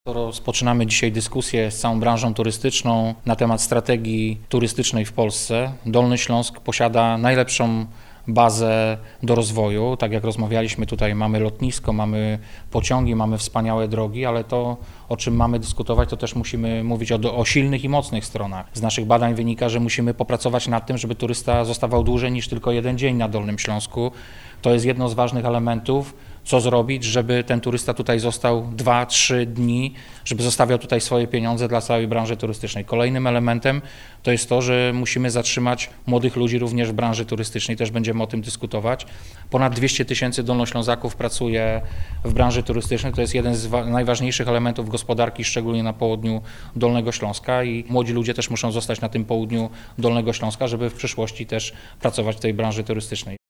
– Z naszych badań wynika, że musimy popracować nad tym, by turysta zostawał dłużej na Dolnym Śląsku i zostawiał pieniądze w branży turystycznej. Kolejnym wyzwaniem jest obecność młodych pracowników w sektorze turystycznym – przyznaje wicemarszałek Wojciech Bochnak.